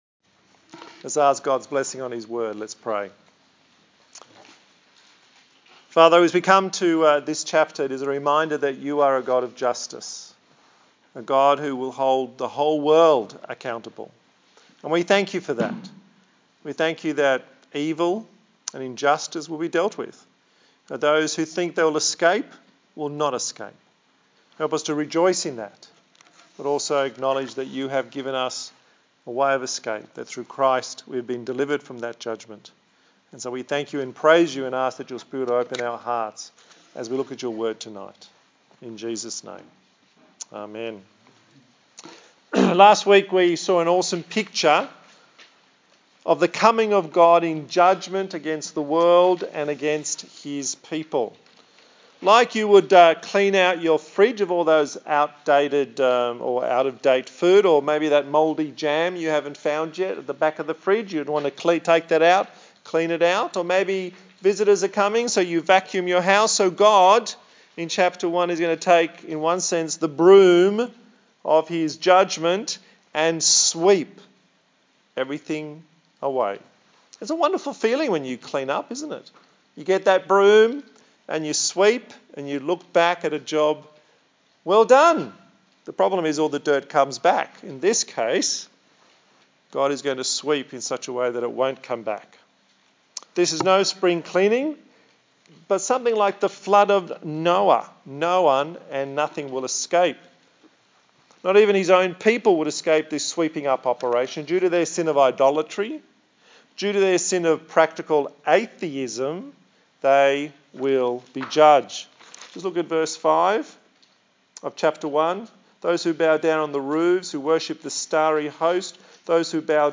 A sermon in the series on the book of Zephaniah